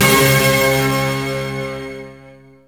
55aj-hit19-f#2.aif